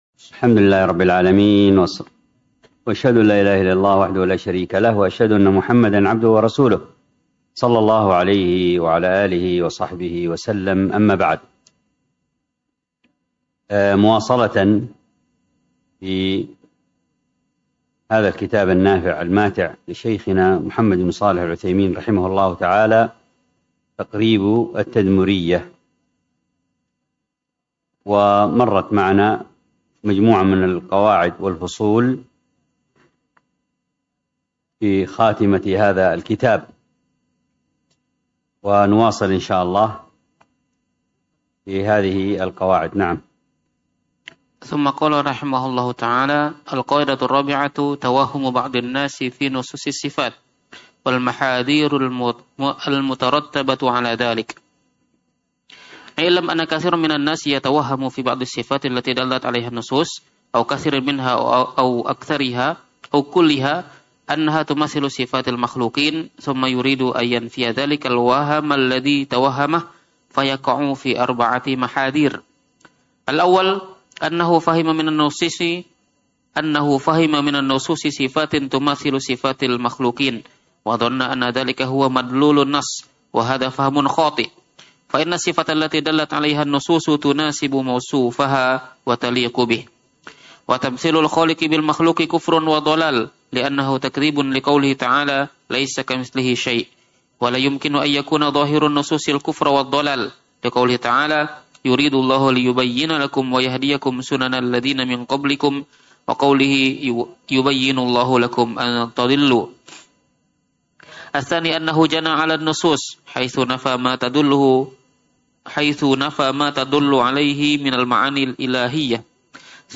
الدرس في كتاب الإمارة 4، باب فضيلة الإمام العادل وعقوبة الجائر والحث على الرفق بالرعية والنهي عن إدخال المشقة عليهم .